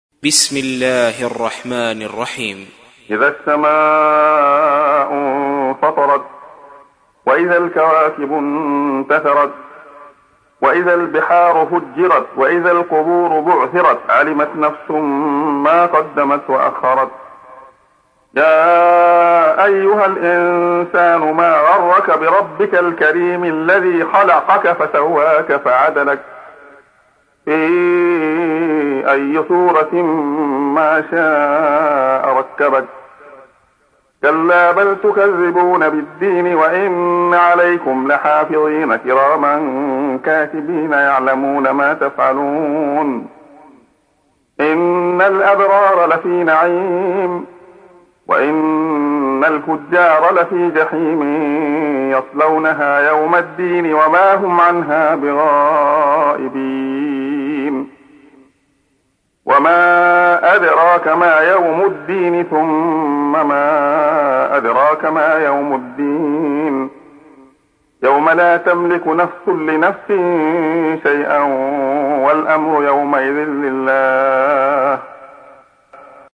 تحميل : 82. سورة الانفطار / القارئ عبد الله خياط / القرآن الكريم / موقع يا حسين